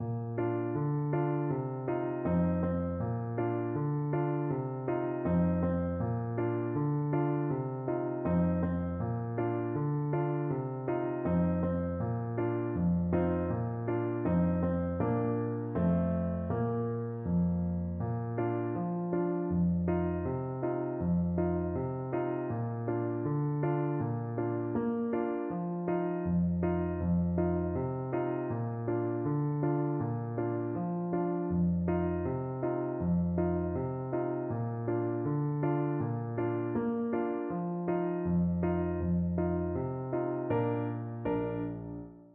Trumpet
This traditional up-beat Passover song is over one thousand years old.
4/4 (View more 4/4 Music)
Bb major (Sounding Pitch) C major (Trumpet in Bb) (View more Bb major Music for Trumpet )
Traditional (View more Traditional Trumpet Music)
world (View more world Trumpet Music)